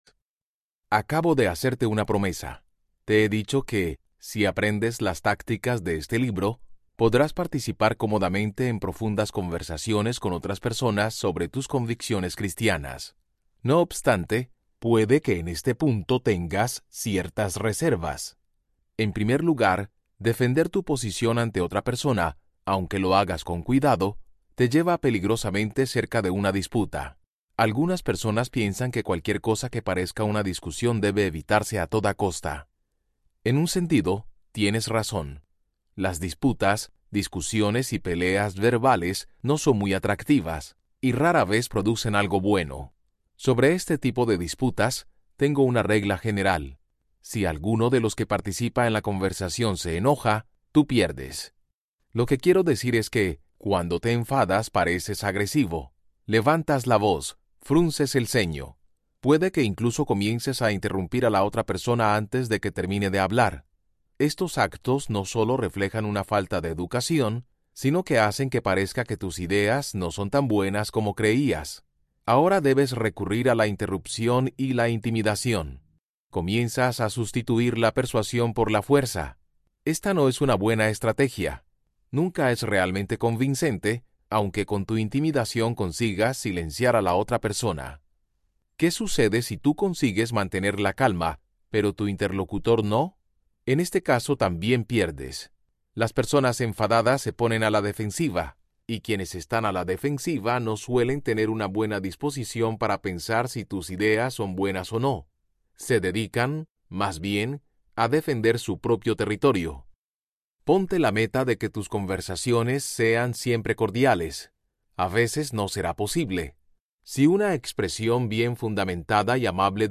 Tácticas Audiobook
Narrator
7.4 Hrs. – Unabridged